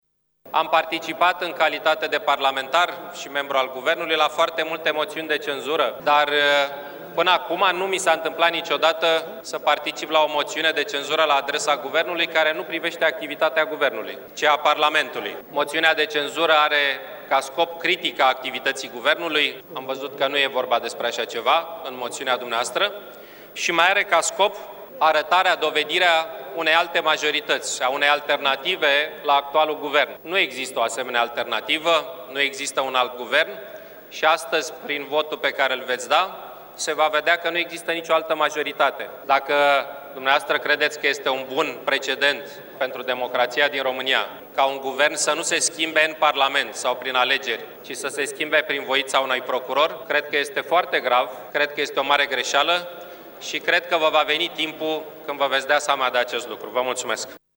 Victor Ponta a declarat, de la tribuna Parlamentului, că moţiunea de cenzură este un drept fundamental al Opoziţiei, dar că e pentru prima dată când participă la un astfel de demers antiguvernamental care nu are ca obiect activitatea Executivului.